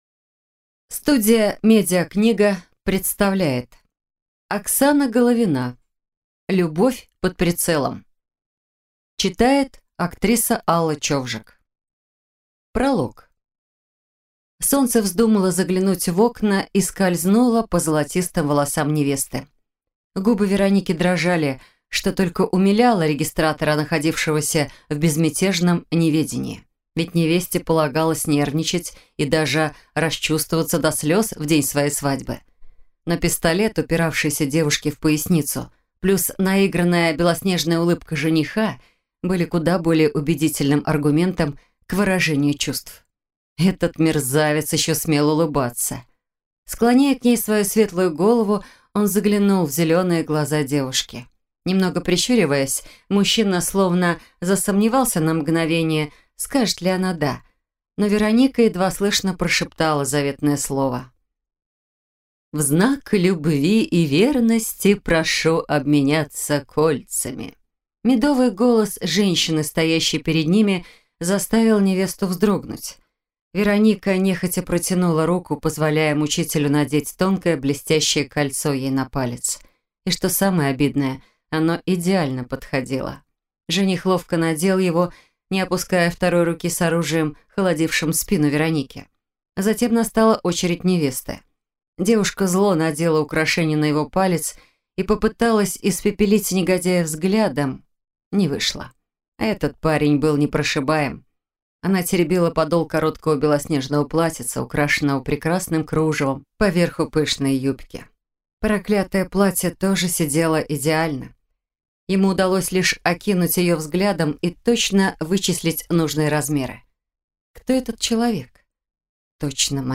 Аудиокнига Любовь под прицелом | Библиотека аудиокниг